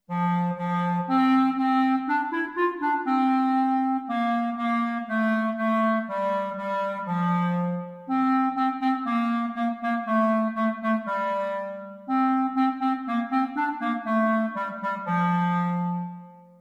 Clarinet Solo